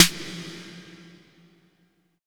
80 VRB SN2-R.wav